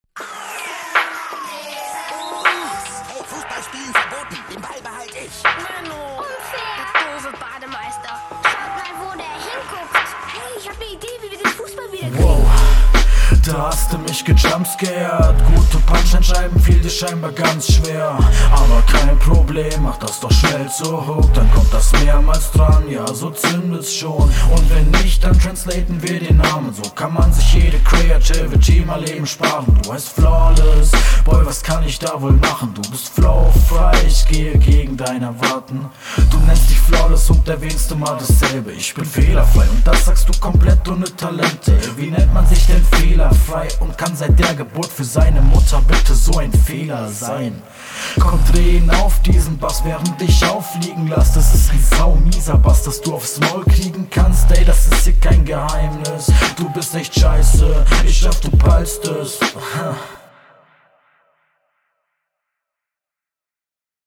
Dein Einstieg gefällt mir auch , sehr laidback gerappt .